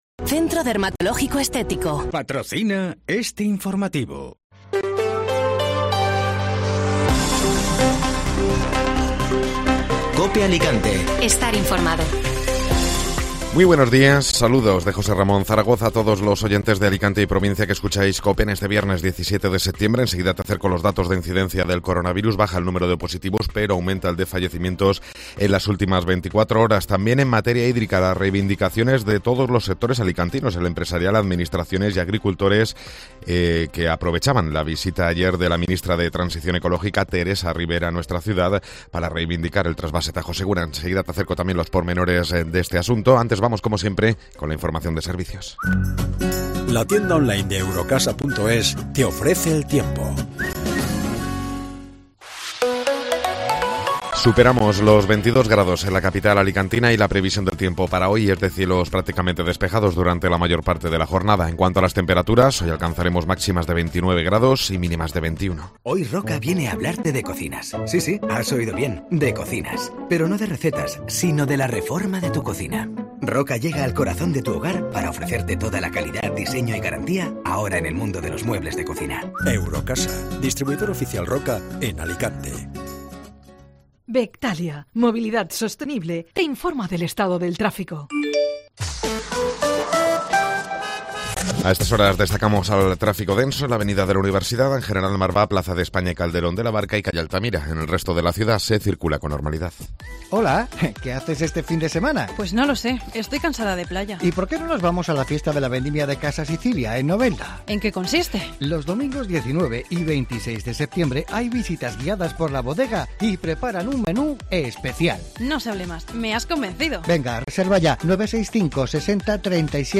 Informativo Matinal (Viernes 17 de Septiembre)